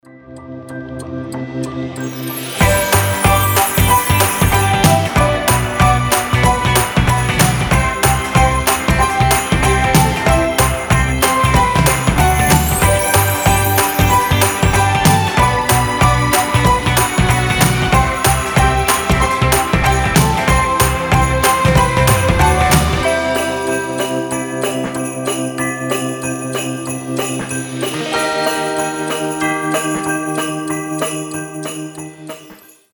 • Качество: 320, Stereo
мелодичные
без слов
добрые
колокольчики
рождественские